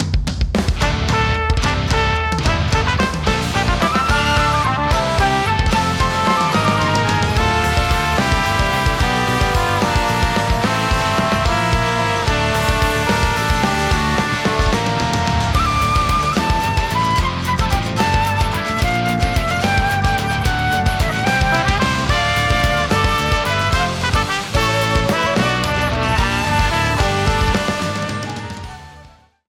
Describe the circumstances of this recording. Ripped from the game trimmed to 29.5 seconds and faded out the last two seconds